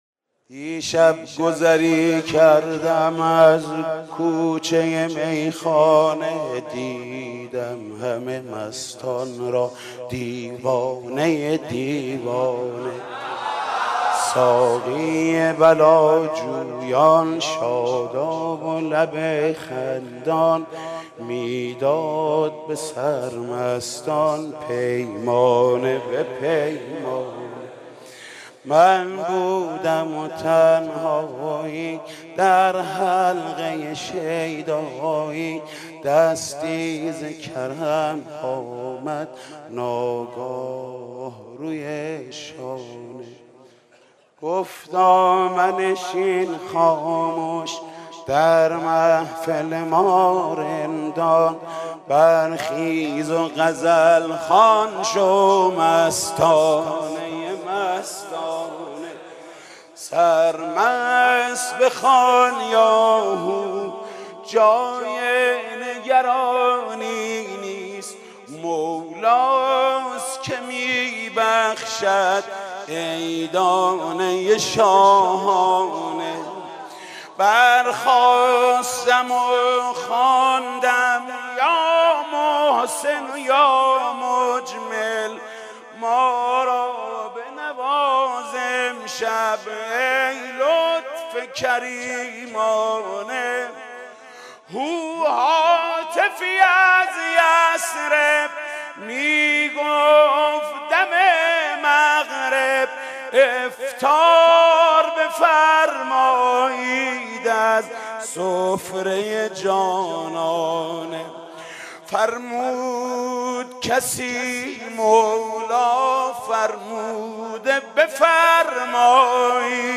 مدح: دیشب گذری کردم از کوچه میخانه